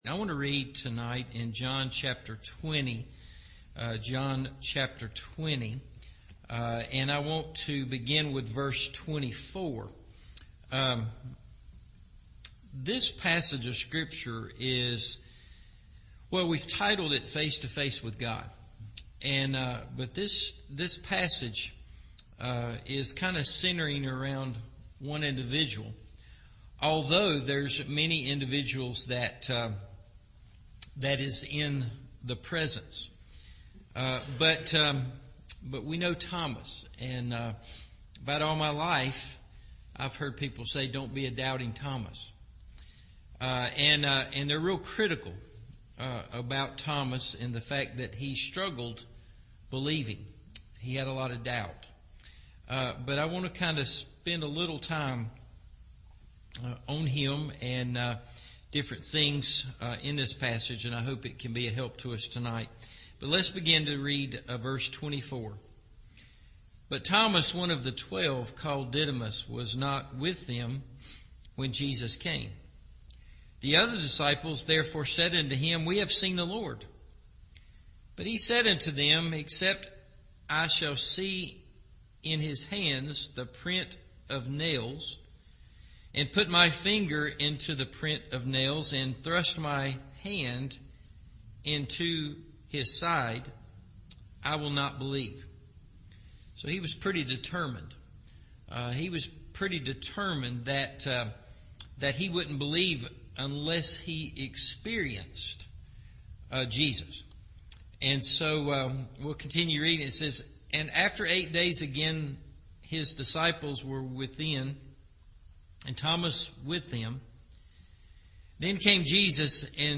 Face To Face – Evening Service